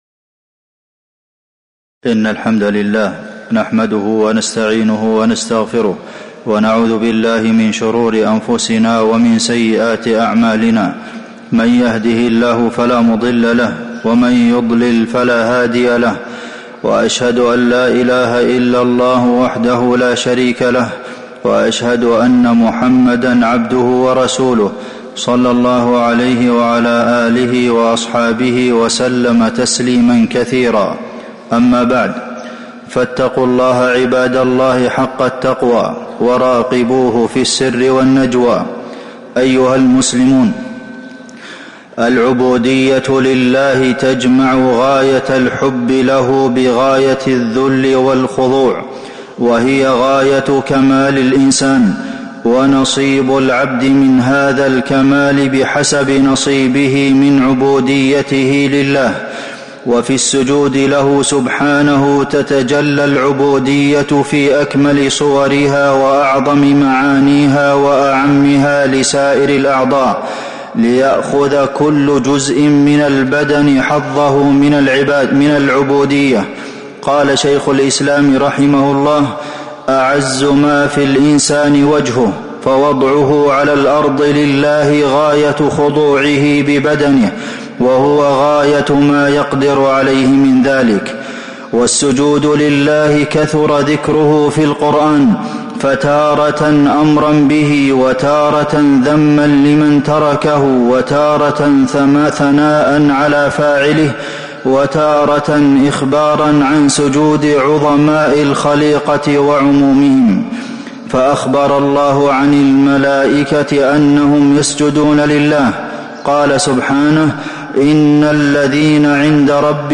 تاريخ النشر ٢٧ جمادى الأولى ١٤٤٦ هـ المكان: المسجد النبوي الشيخ: فضيلة الشيخ د. عبدالمحسن بن محمد القاسم فضيلة الشيخ د. عبدالمحسن بن محمد القاسم العبودية لله